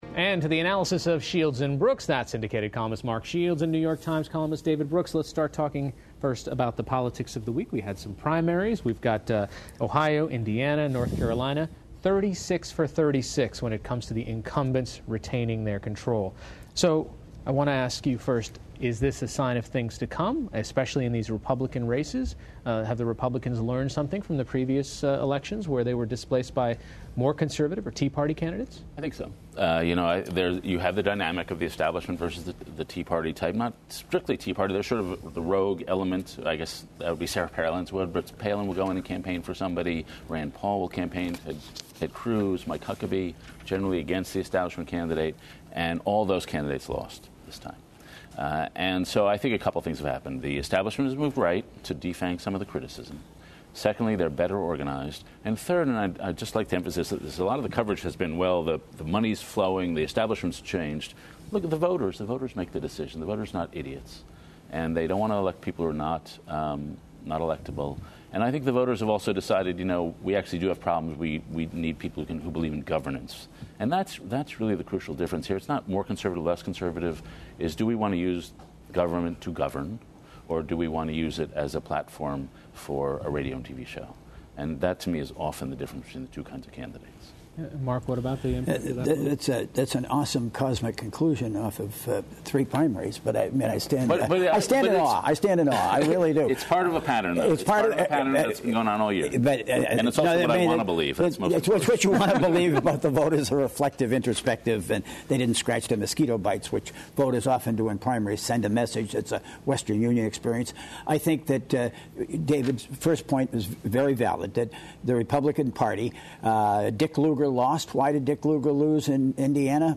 That's syndicated columnist Mark Shields and New York Times columnist David Brooks.